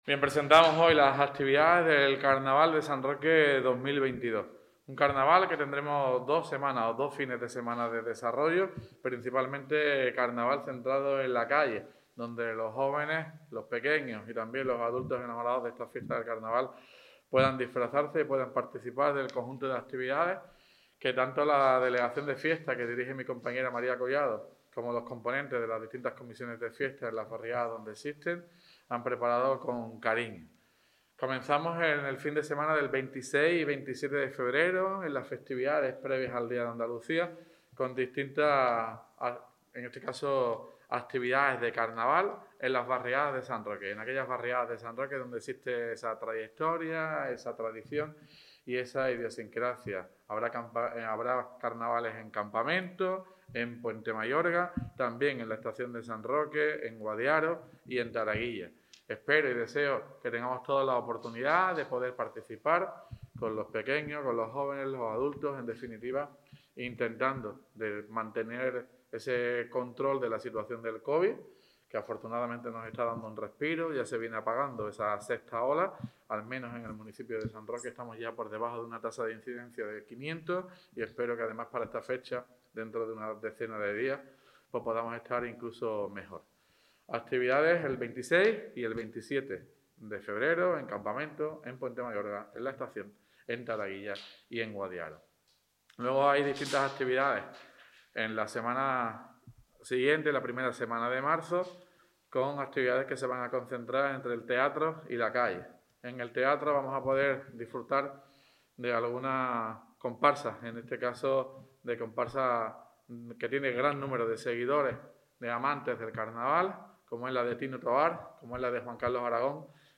TOTAL_ALCALDE_PRESENTACIÓN_CARNAVALES.mp3